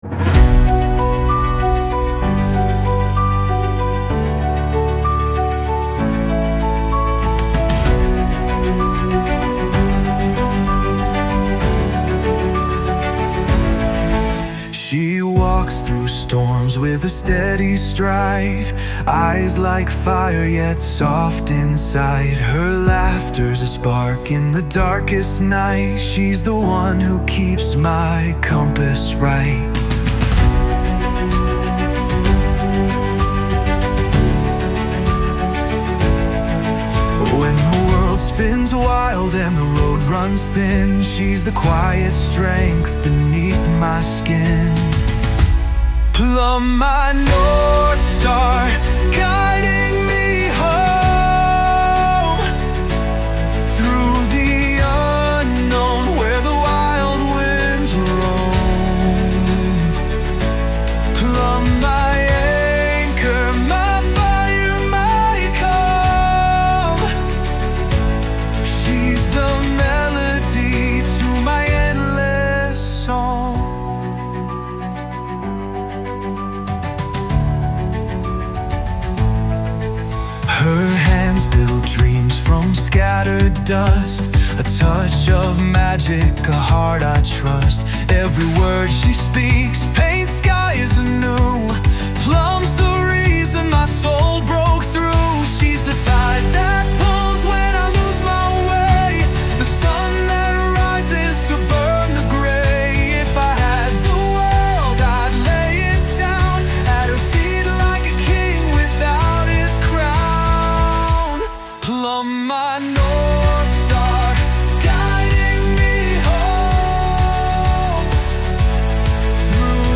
This anniversary release brings together a studio album and a cinematic spectacular, capturing the memories, emotions, and time shared over the past year.
Delve into the soulful tracks of The Anniversary Album.
by Plum Collective | The Anniversary Album